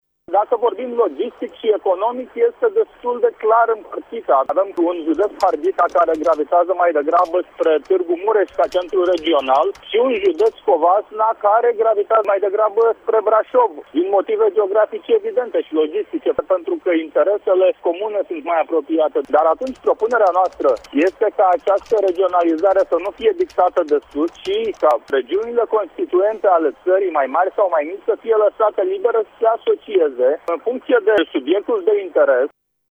în emisiunea Sens Unic, de la Radio Tîrgu-Mureş